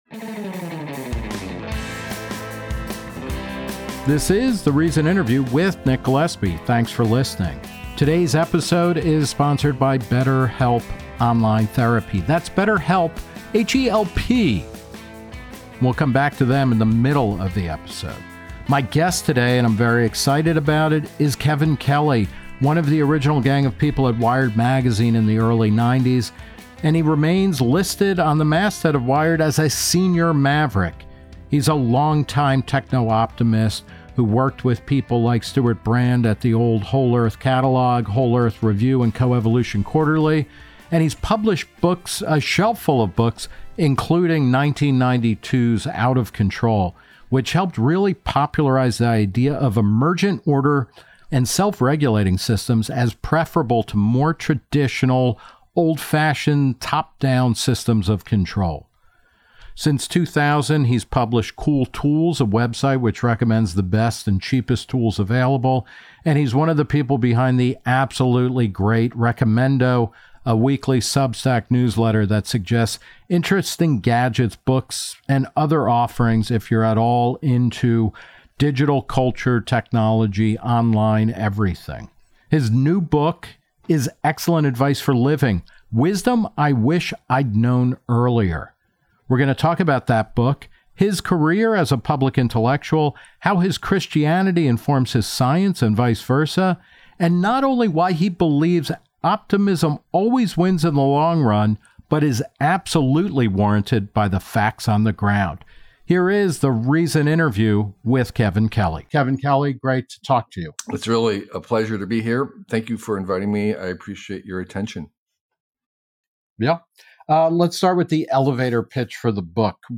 Wired's "senior maverick" talks with Nick Gillespie about his new book of accumulated wisdom, backlash against tech, and why the future still looks bright.